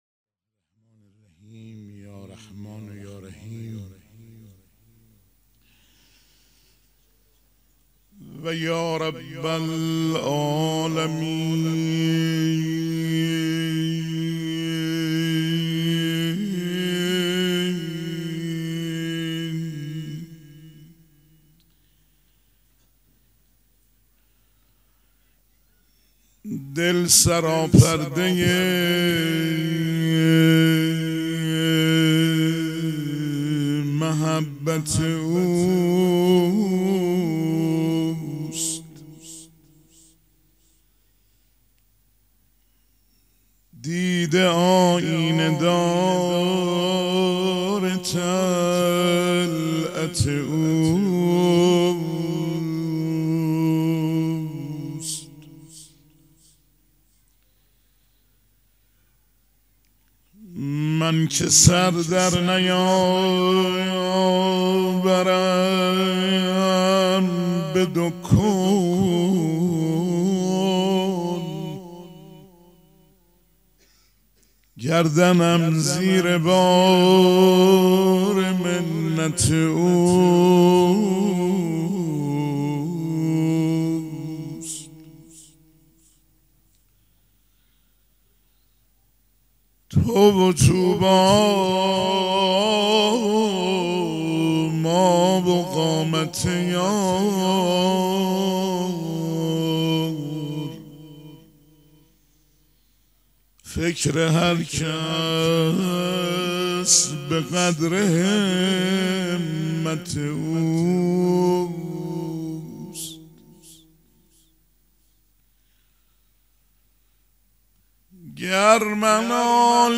پیش منبر
مداح
مراسم عزاداری شب دوم